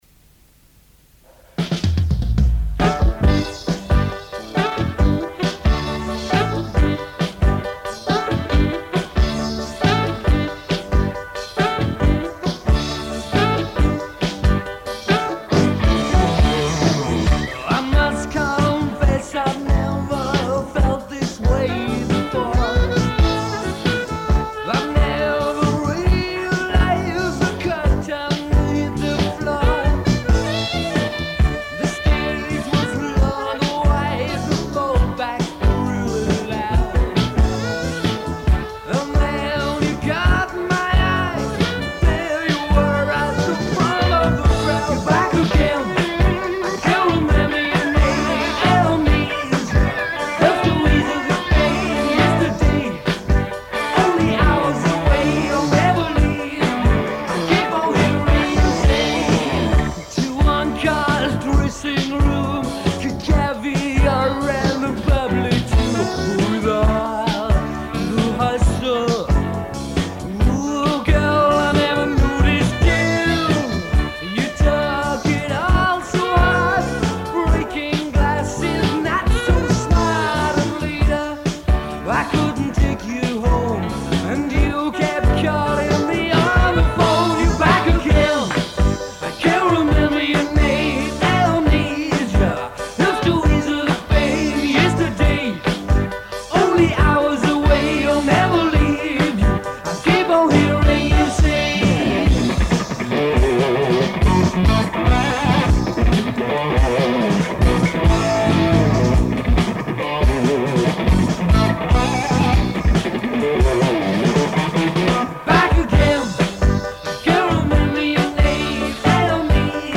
being played live in the studio, no multi-tracking.